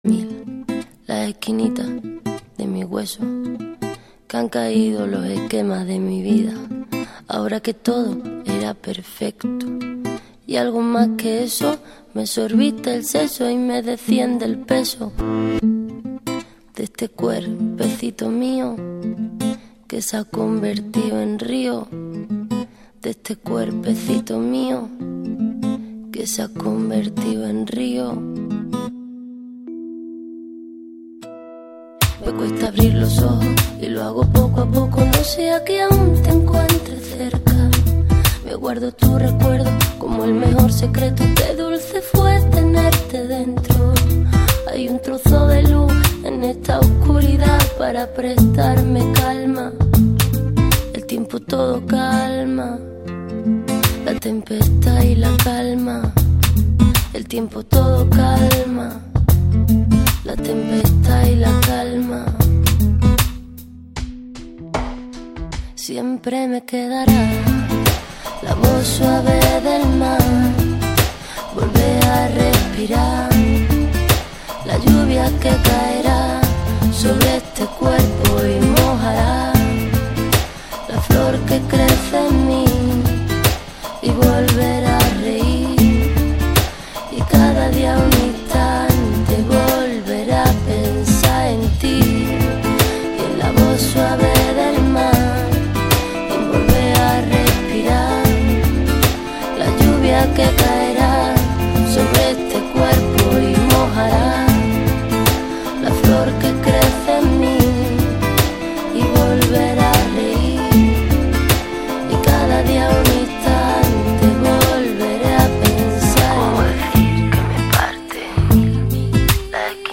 какаято девка чето поет на испанском или мексиканском )))